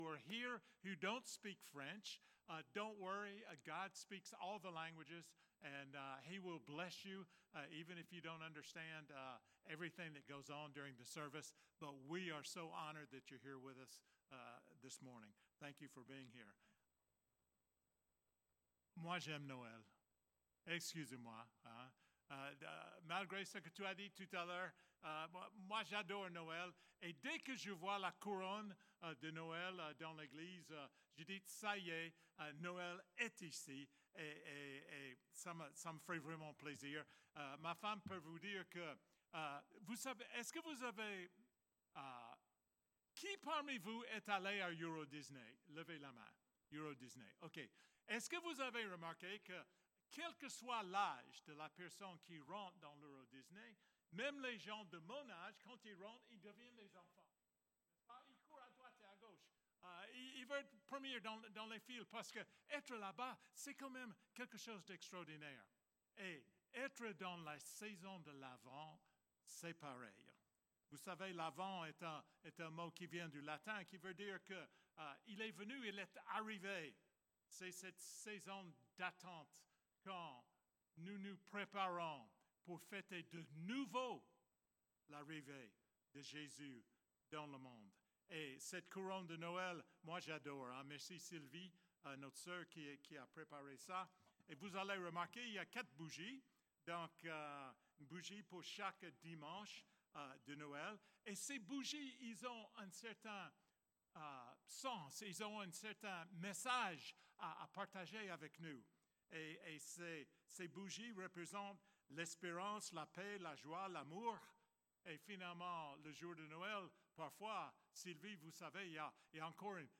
29 novembre 2025 Lumière d’espérance Prédicateur